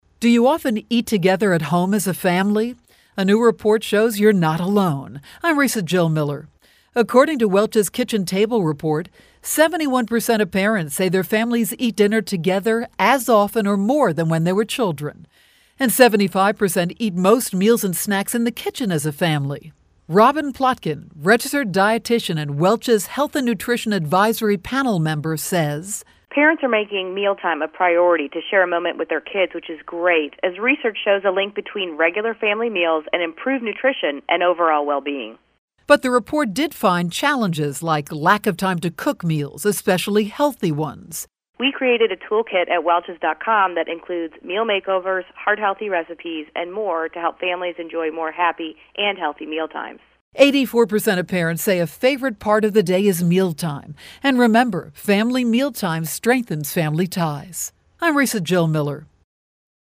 January 30, 2013Posted in: Audio News Release